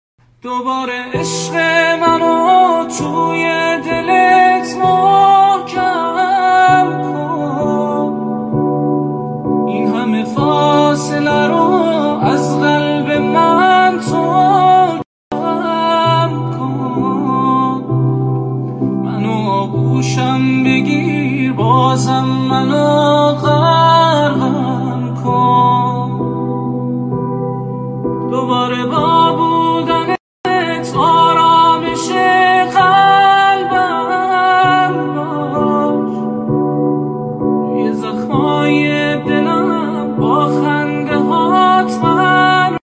حس و حال : دلی